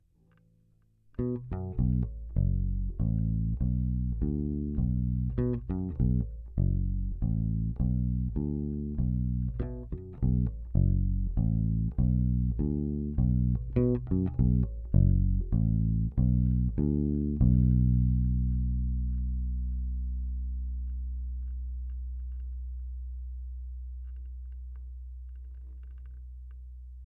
Snímač by měl být DiMarzio DP122 + přepínač serie/paralel
prsty série